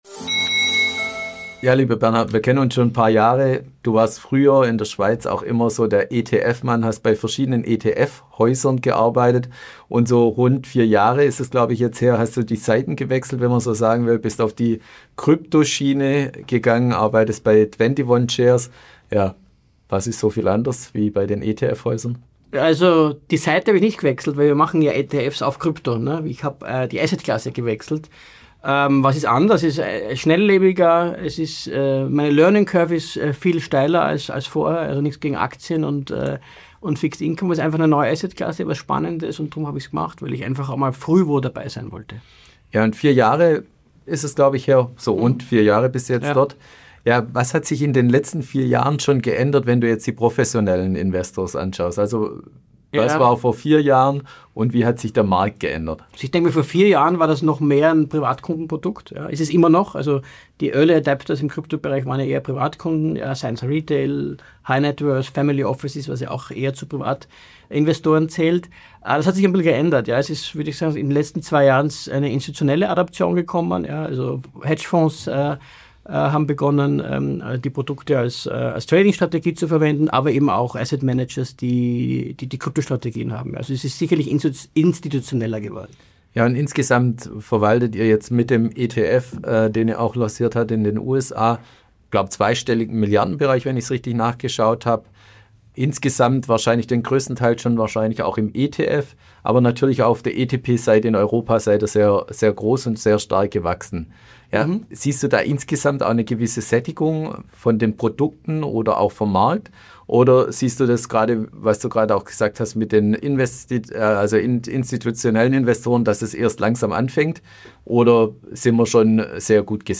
Podcast-News